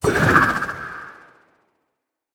Minecraft Version Minecraft Version snapshot Latest Release | Latest Snapshot snapshot / assets / minecraft / sounds / item / trident / riptide3.ogg Compare With Compare With Latest Release | Latest Snapshot
riptide3.ogg